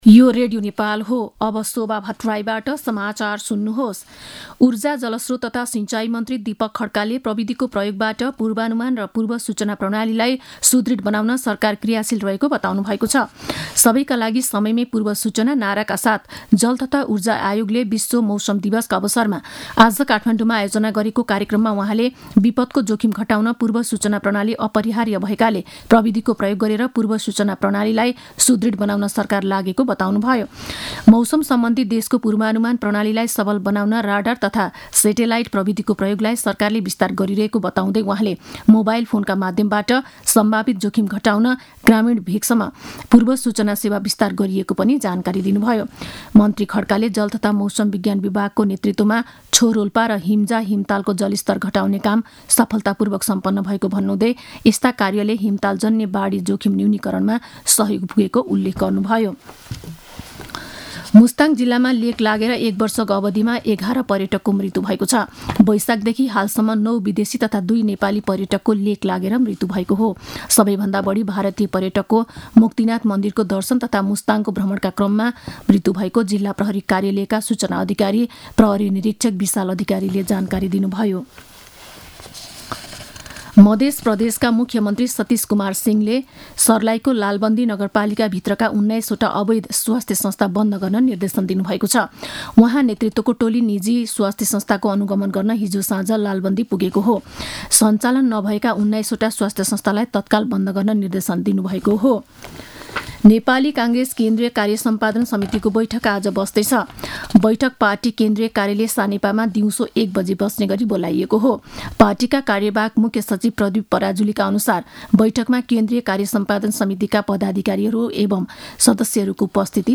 मध्यान्ह १२ बजेको नेपाली समाचार : १० चैत , २०८१
12-am-news-1-7.mp3